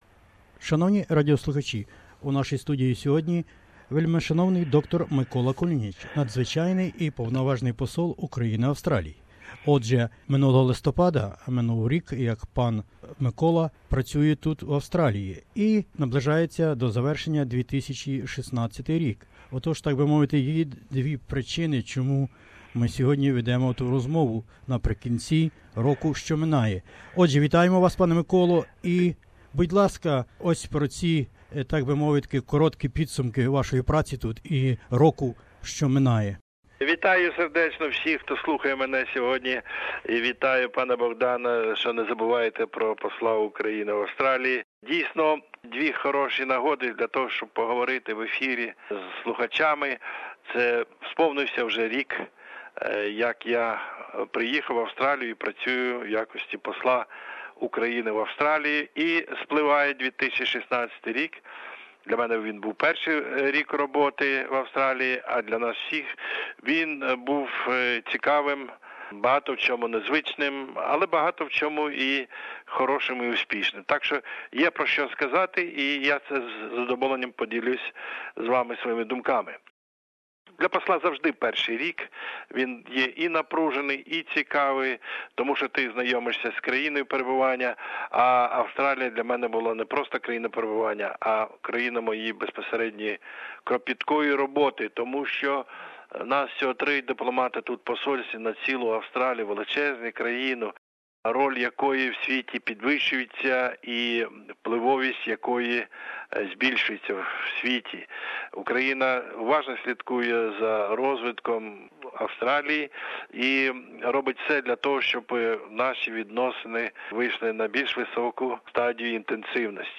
The Honourable Dr Mykola Kulinich, the Ambassador of Ukraine in Australia Source: SBS Ukrainian